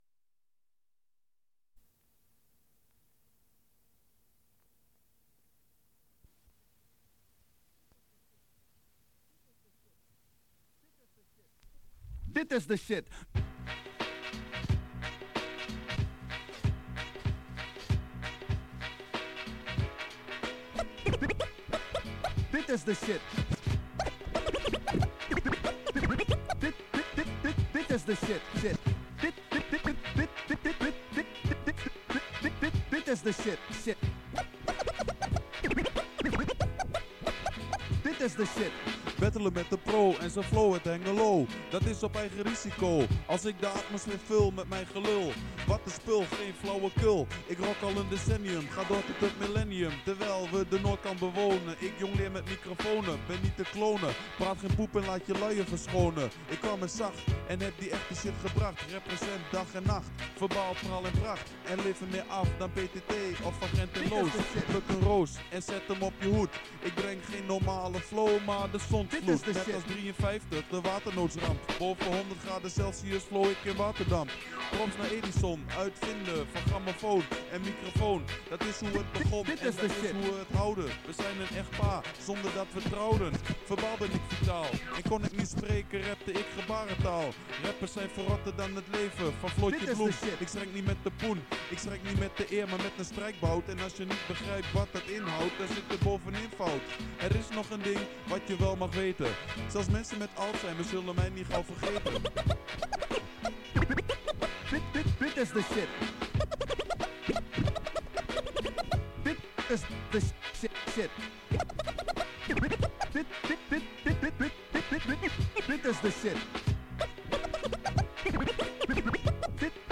Onderdeel van Battle of the banks live in Atak Enschede